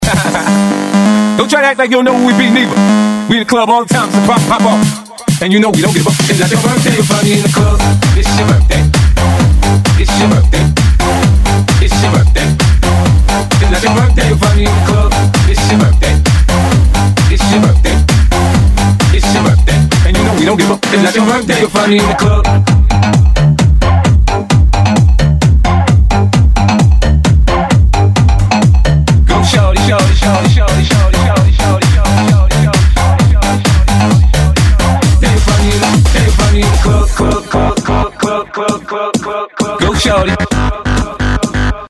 Назад в Мр3 | House